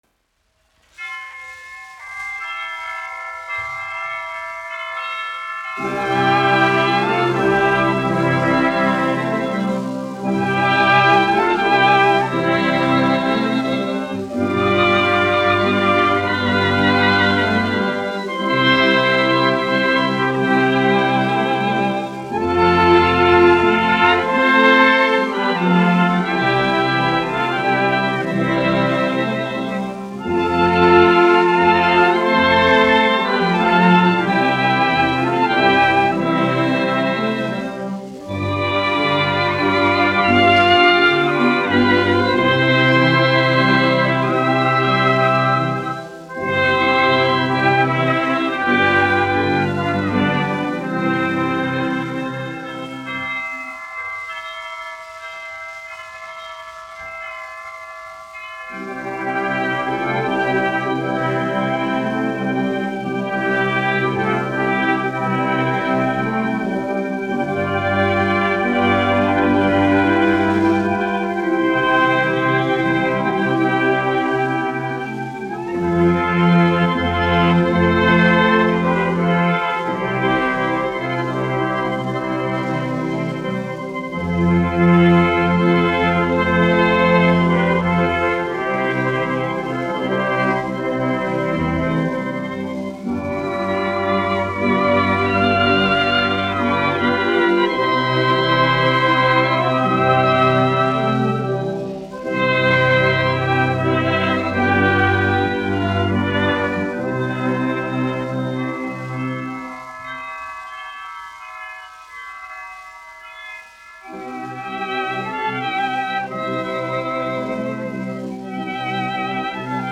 1 skpl. : analogs, 78 apgr/min, mono ; 25 cm
Ziemassvētku mūzika
Latvijas vēsturiskie šellaka skaņuplašu ieraksti (Kolekcija)